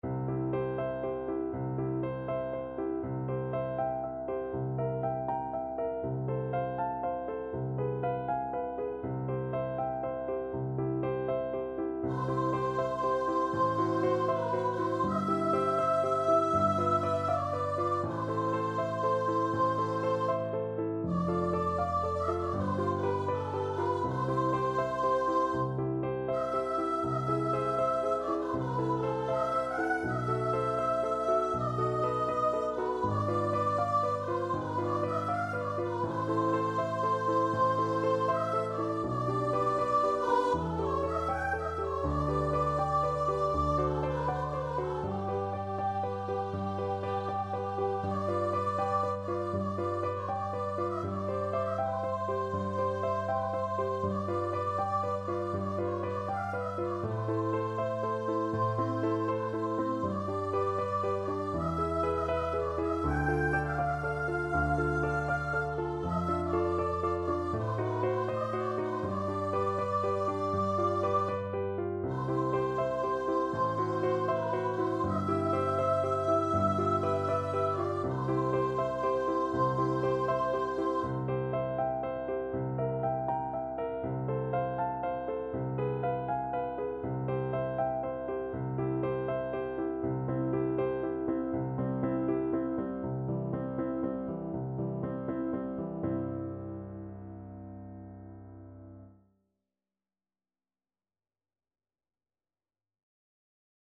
Andante
4/4 (View more 4/4 Music)
Classical (View more Classical Soprano Voice Music)